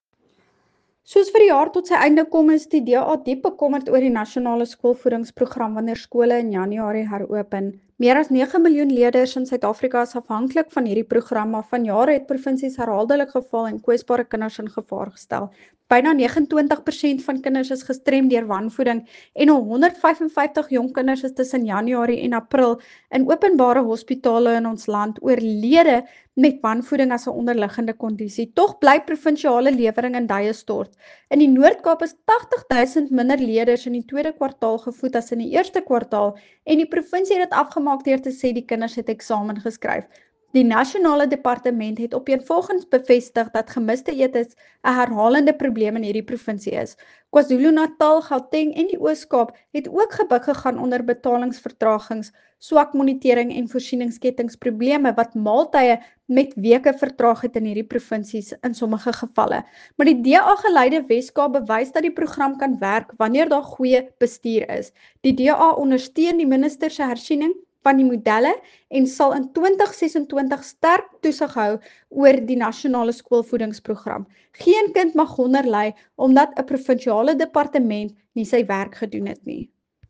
Afrikaans soundbite by Ciska Jordaan MP.